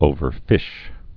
(ōvər-fĭsh)